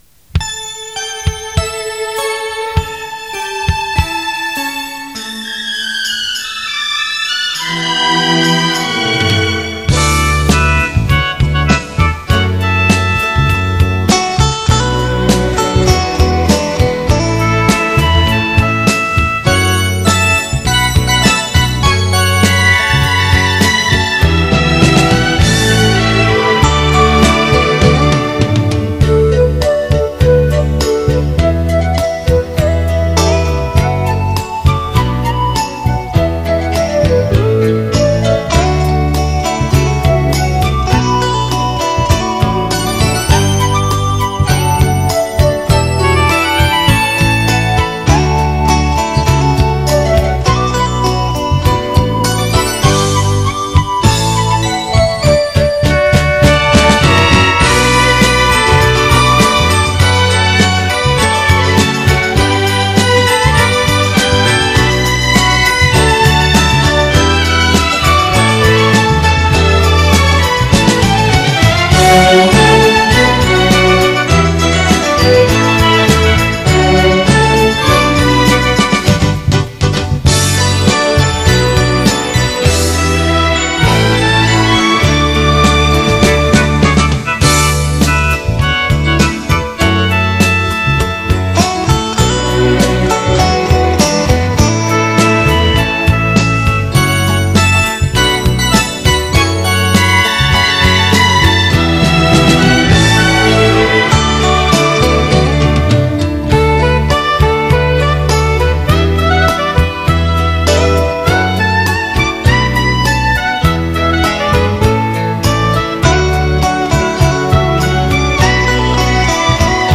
（环绕立体声）
键盘
吉他
提琴